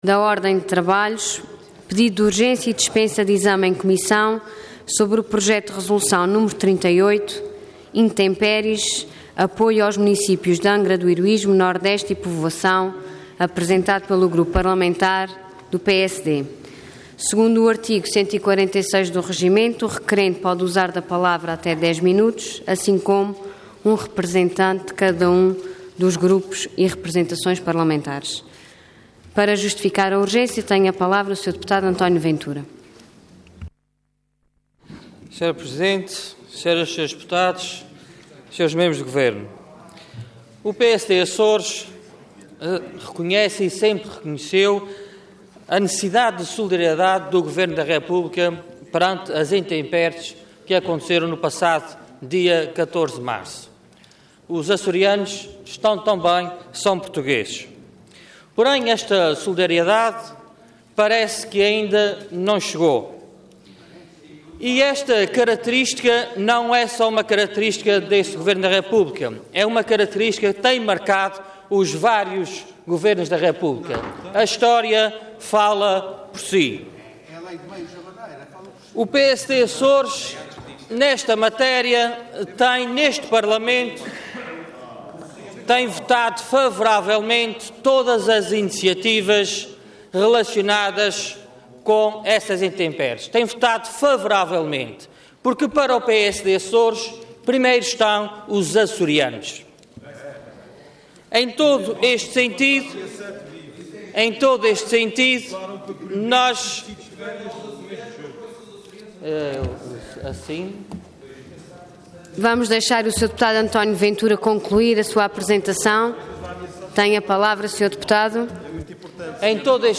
Intervenção Pedido de urgência e dispensa de exame em comissão Orador António Ventura Cargo Deputado Entidade PSD